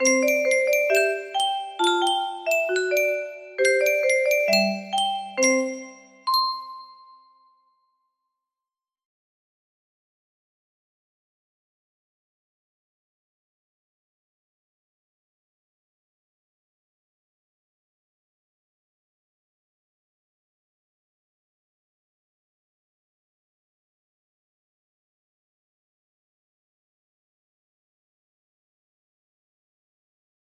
Quick little rendition